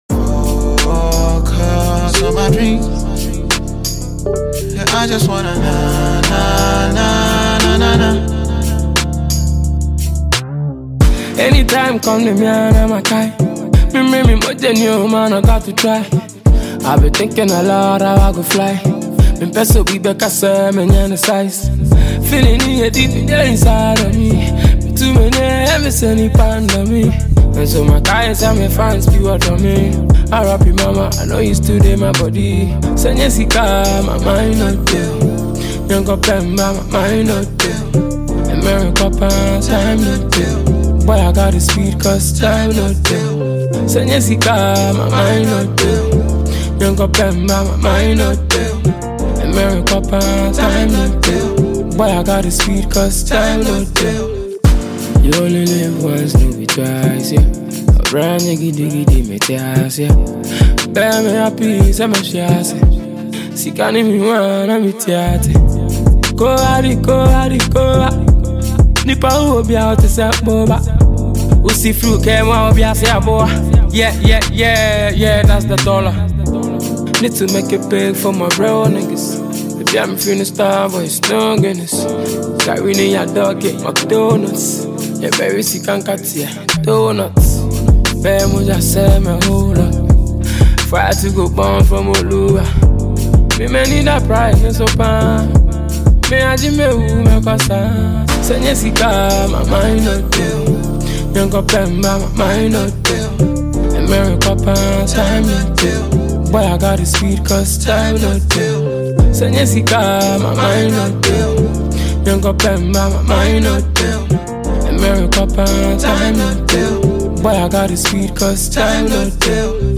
Freestyle music